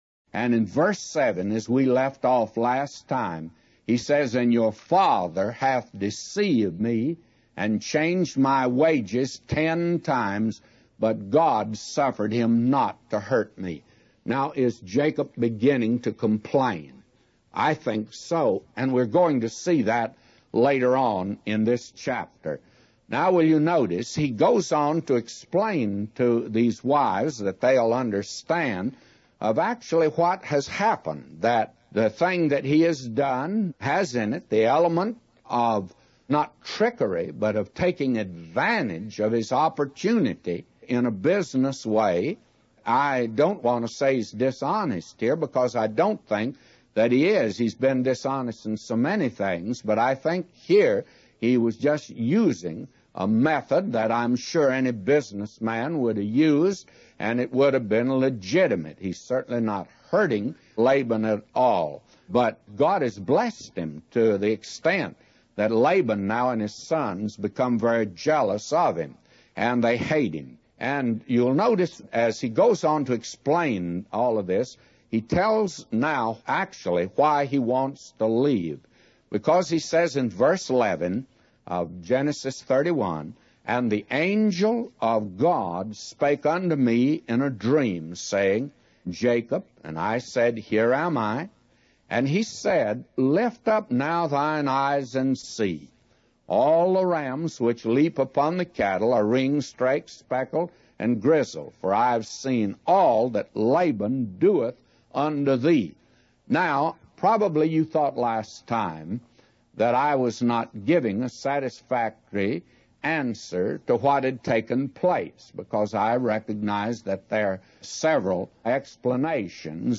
A Commentary By J Vernon MCgee For Genesis 31:7-999